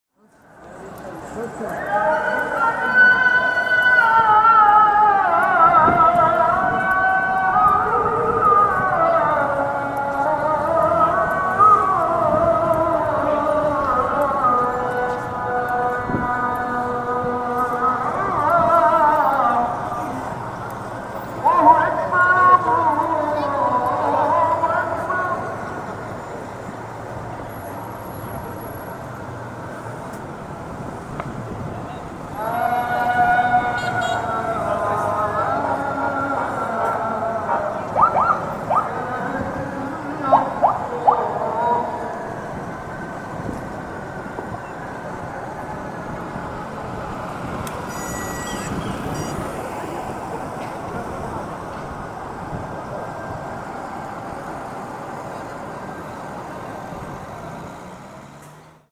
Street Ambience Of Istanbul Sound Effect
Sound Effects / Street Sounds 11 Feb, 2025 Street Ambience Of Istanbul Sound Effect Read more & Download...
Street-ambience-of-Istanbul.mp3